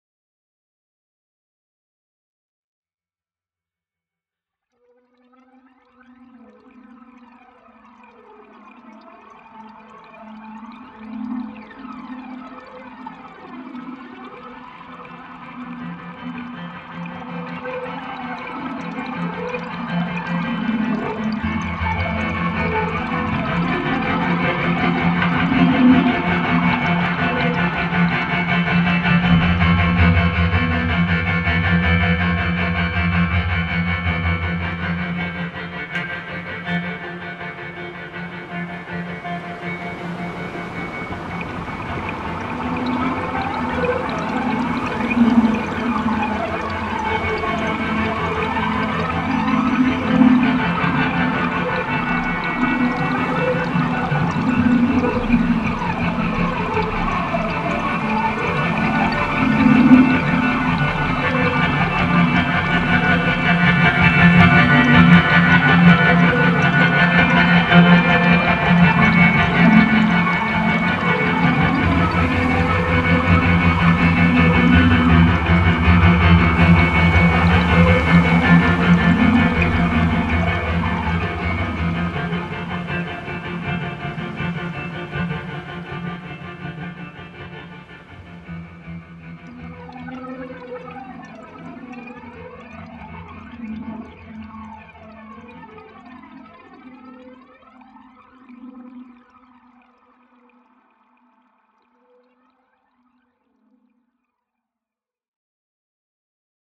Très bonnes musiques d’ambiances, cela lance le voyage de belle manière, bravo.
« Aux frontières de la vrume » et « terres interdites » sont inquiétants à souhait, je m’y vois vraiment !